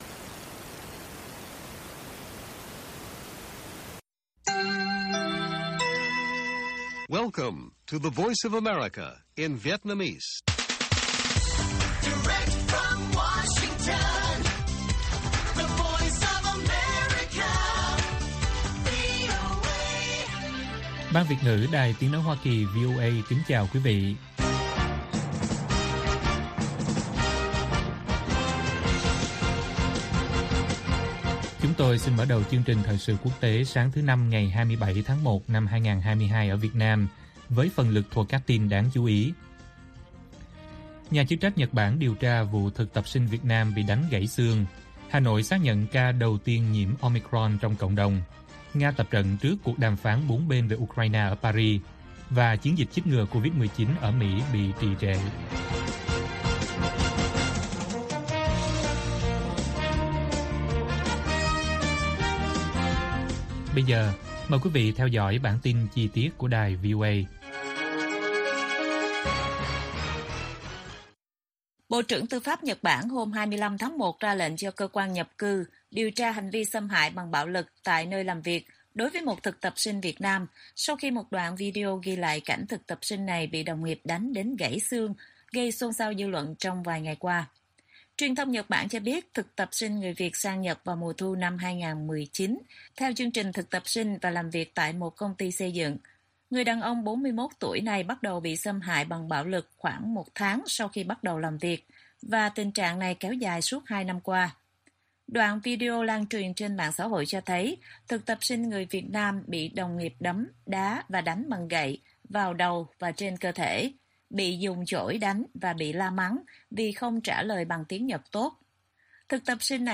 Bản tin VOA ngày 27/1/2022